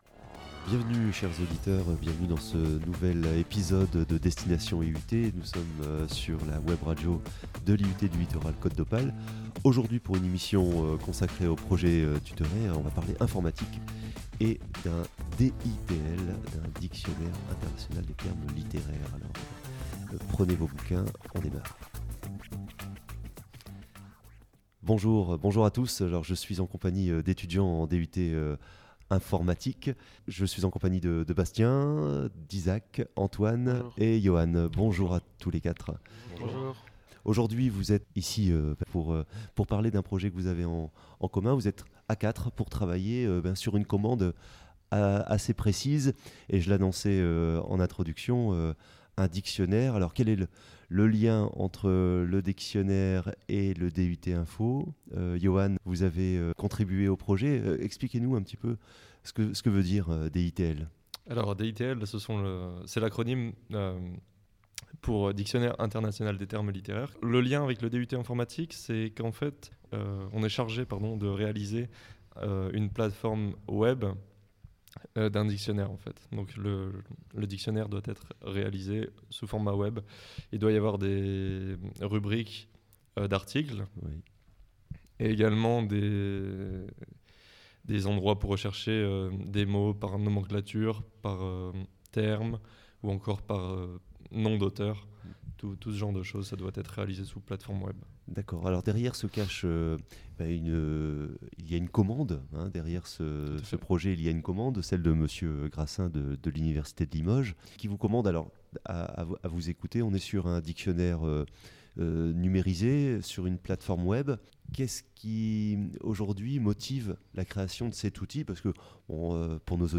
Interviewés
Étudiants en DUT  informatique :